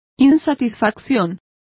Complete with pronunciation of the translation of dissatisfaction.